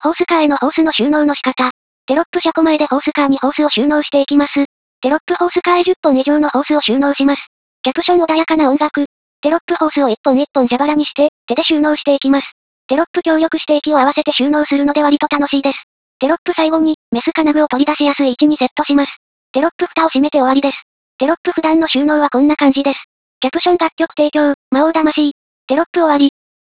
音声解説（ダウンロード）